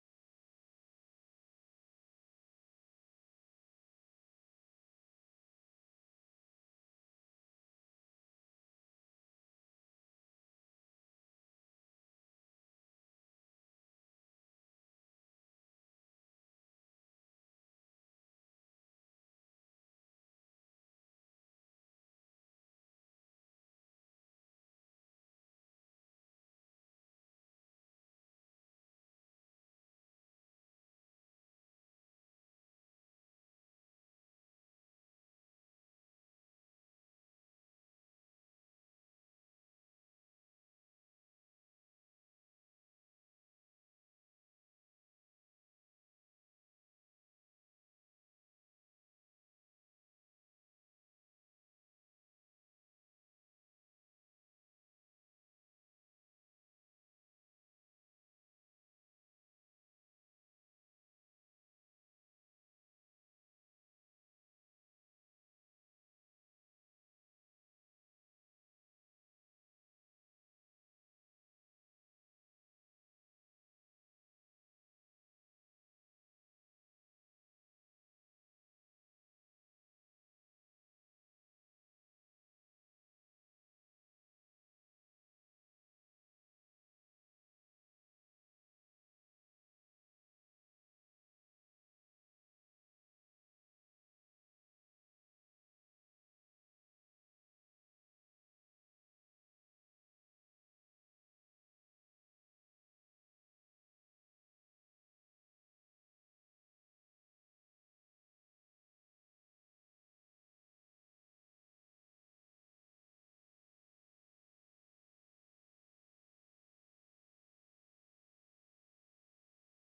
Our worship team leads us in some amazing worship.